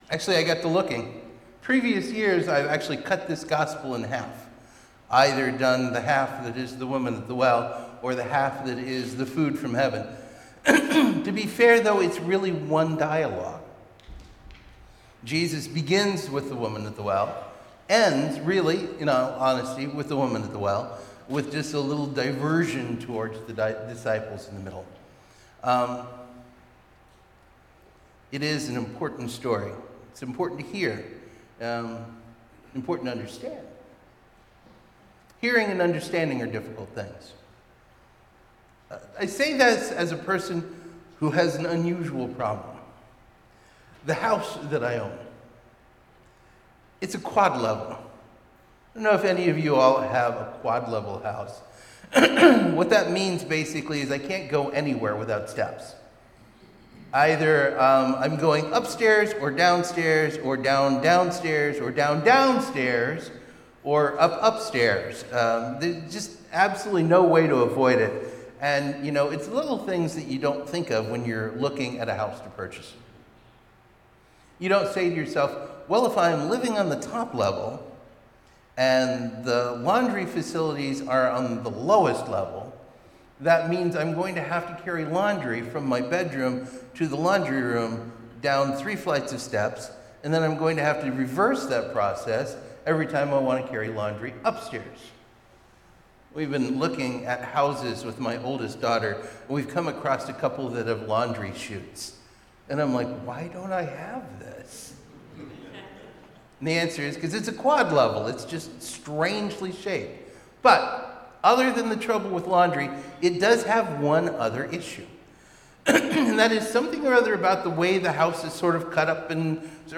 trust-listen-respond-sermon-march-8-2026.mp3